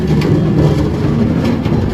minecart_base.ogg